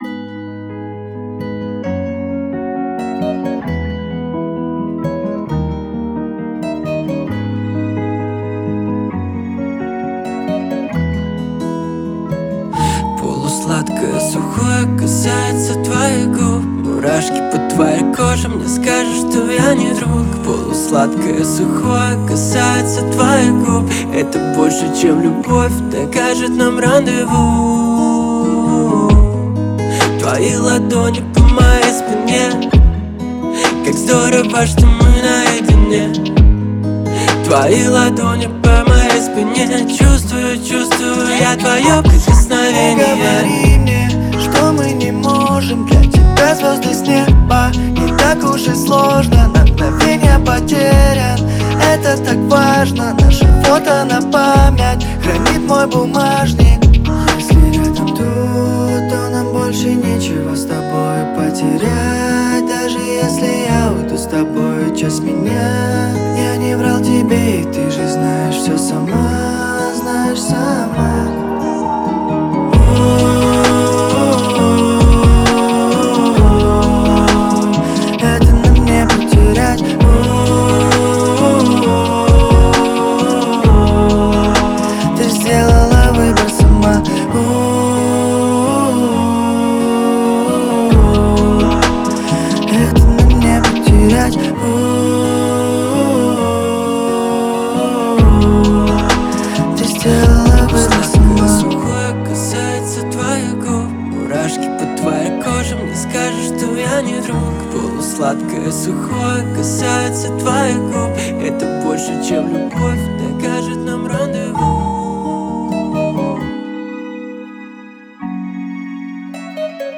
это мощная композиция в жанре хип-хоп